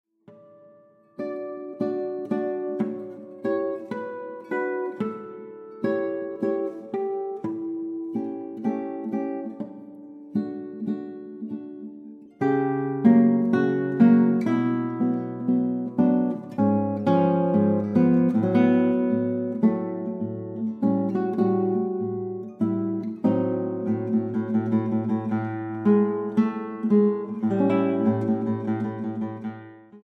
guitarra